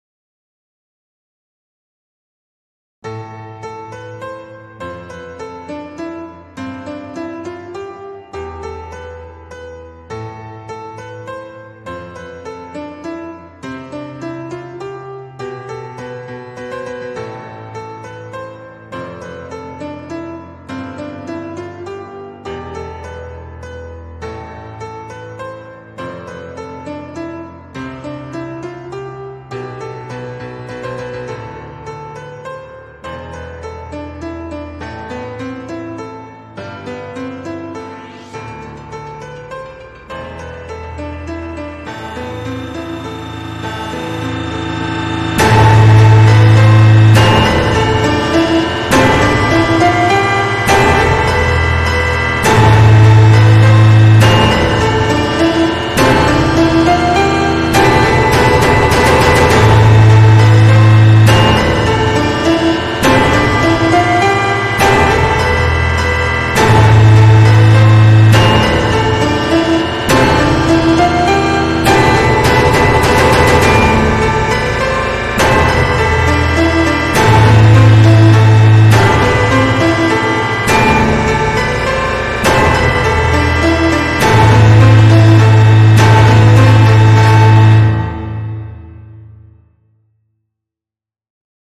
Black MIDI: RWI's Free Sound Effects Free Download